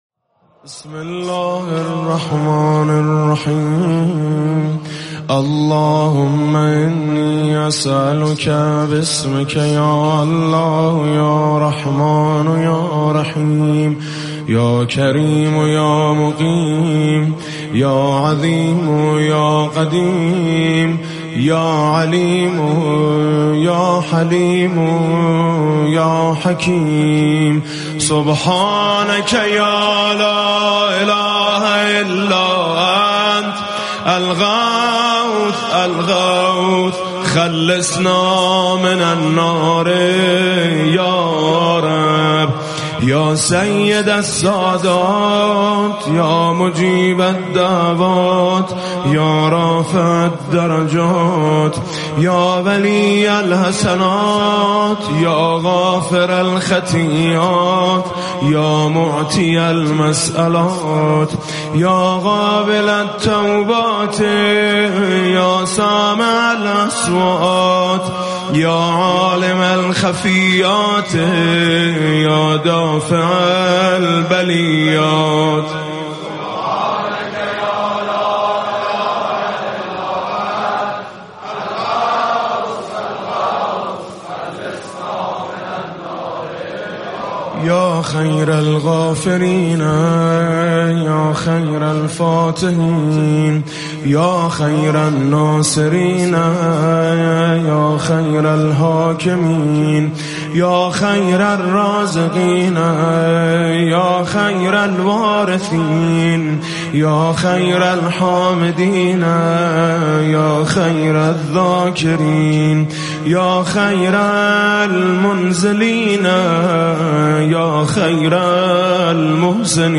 دعای جوشن کبیر و قرآن به سر را با صدای میثم مطیعی | سایت جامع ماه مبارک رمضان